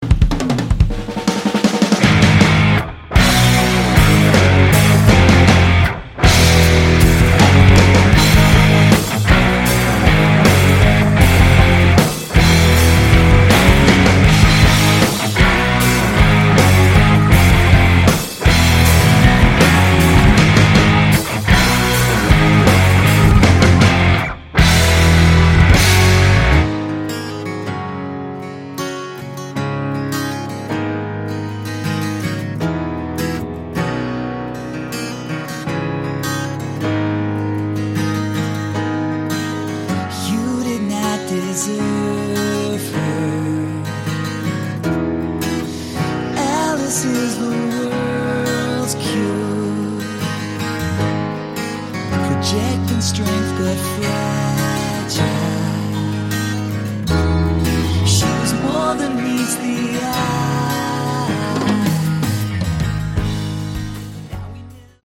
Category: Prog Rock
vocals, guitar
keyboards
drums
bass, vocals